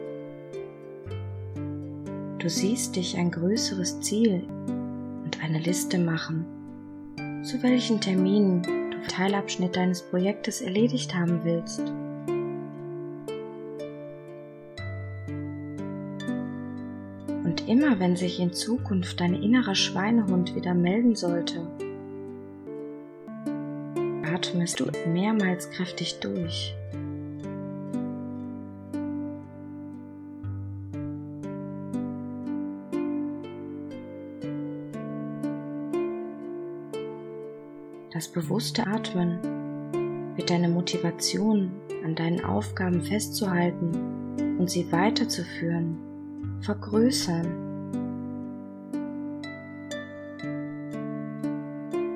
Mit unserer geführten Hypnose „Aufschieberei auflösen“ sagen Sie der Prokrastination (Aufschieberei) den Kampf an.